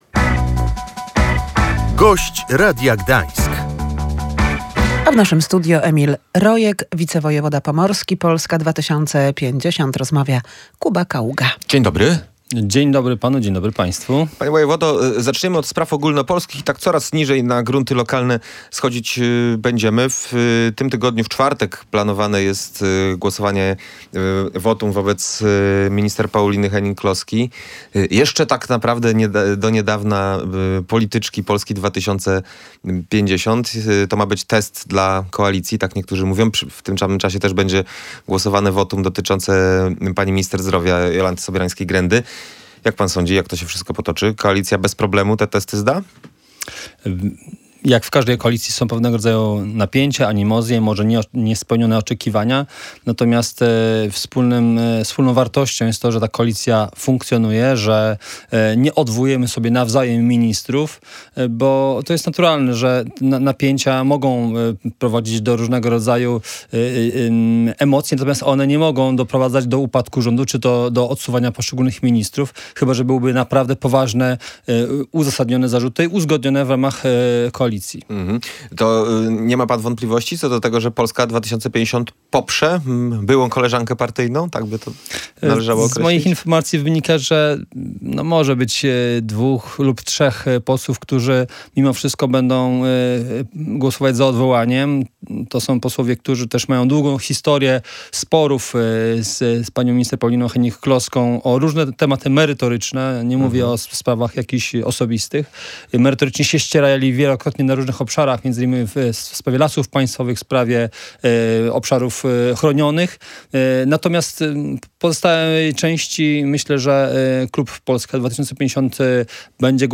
Zakończyły się badania geologiczne i są obiecujące – mówił w Radiu Gdańsk wicewojewoda pomorski Emil Rojek.
Jak podkreślał Gość Radia Gdańsk, miejsce to będzie można eksploatować przez prawie 30 lat.